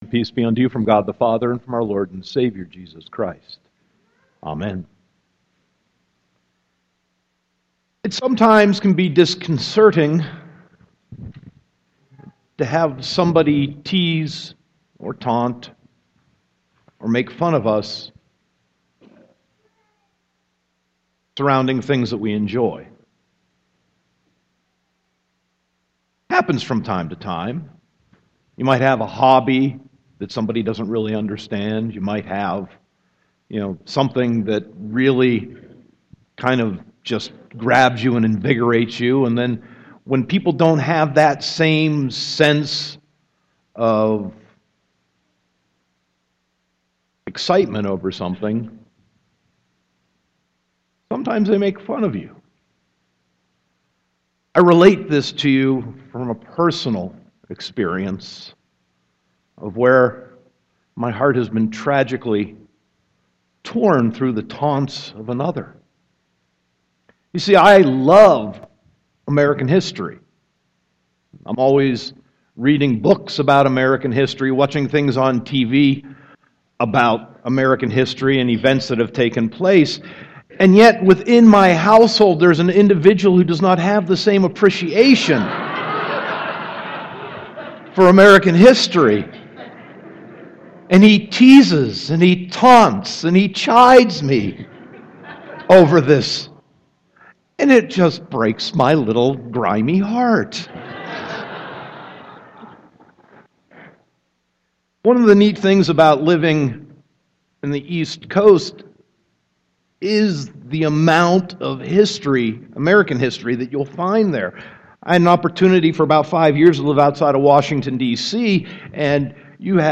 Sermon 4.3.2016